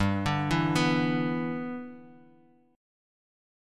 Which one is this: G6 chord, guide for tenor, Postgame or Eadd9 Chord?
G6 chord